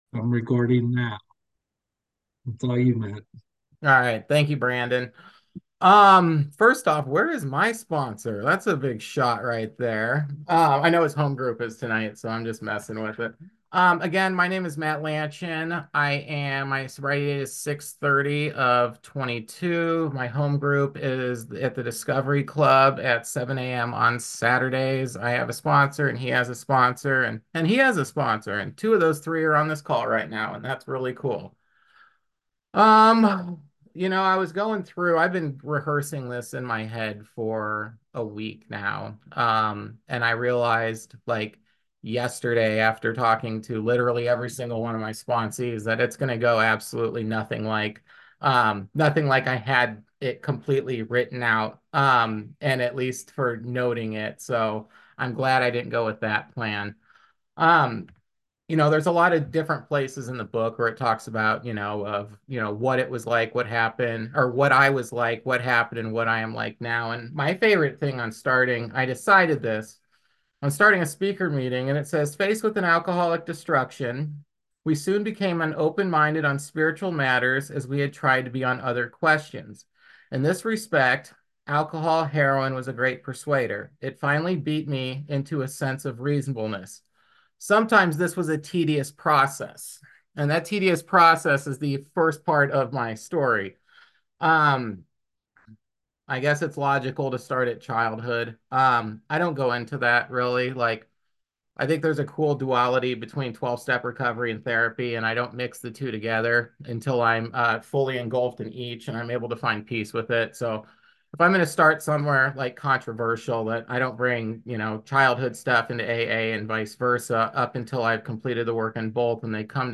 Speaking at the Sharing of the Gift Meeting